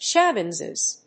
音節shab･bi･ness発音記号・読み方ʃǽbinəs